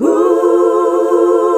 HUH SET F.wav